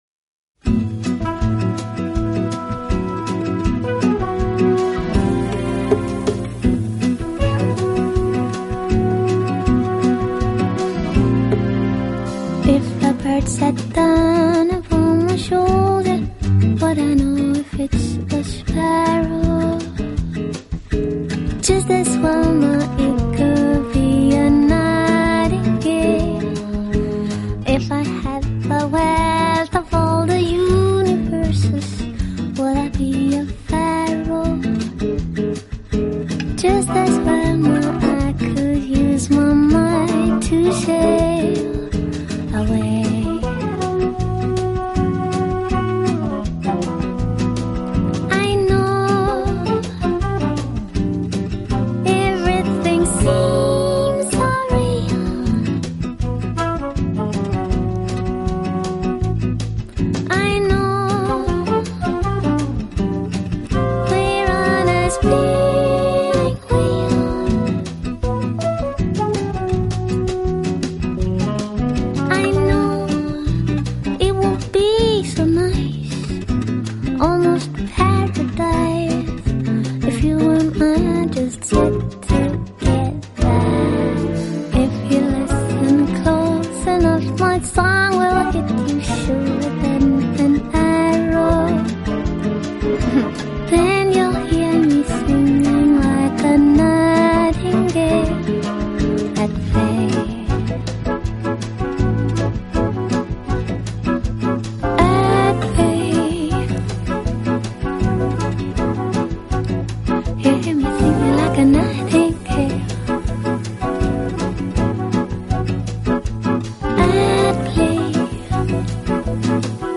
收录大量Bossa Nova风格曲目
全片除了淡淡的吉他弦音，很少其他
听来轻松、舒爽，没有压力。